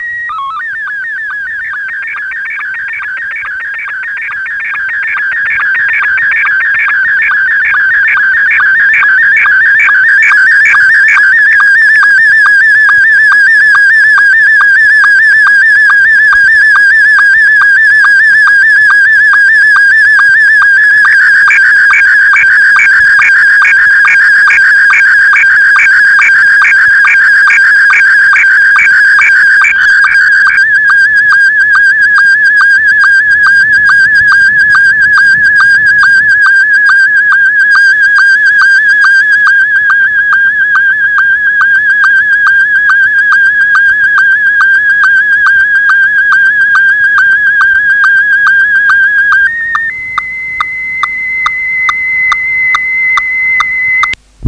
sstvCQ.wav